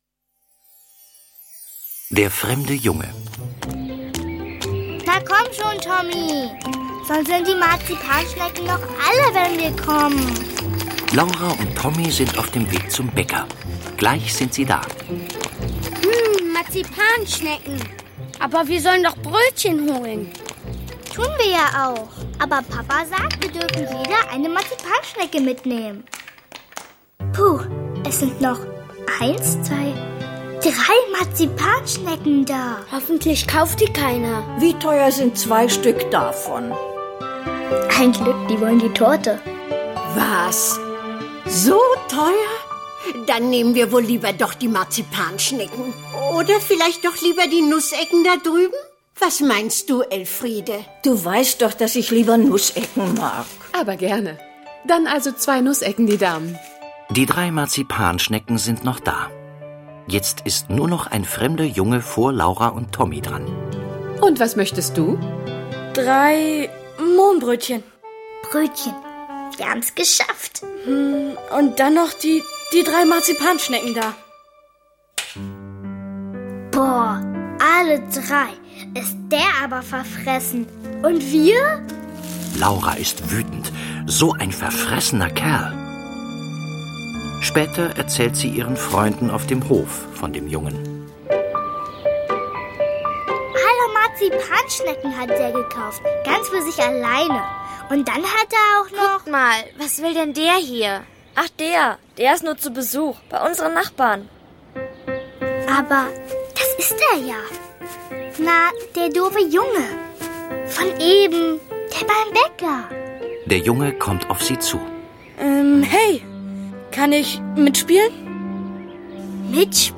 Tonspur der TV-Serie, Folge 1+2.